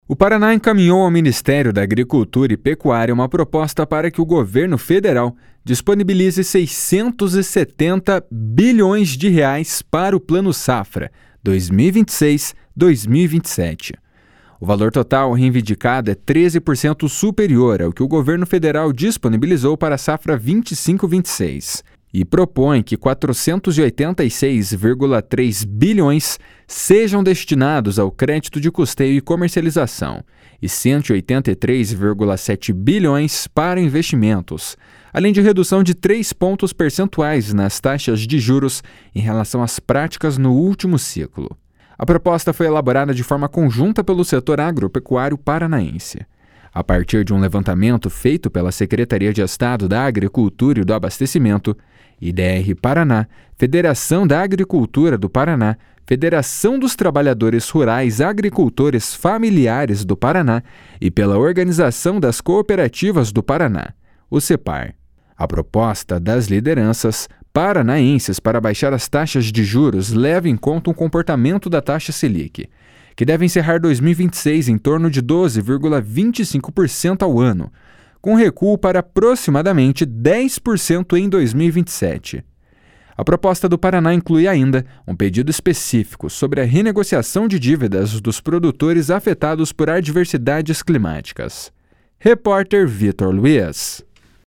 A proposta do Paraná inclui, ainda, um pedido específico sobre a renegociação de dívidas dos produtores afetados por adversidades climáticas. (Repórter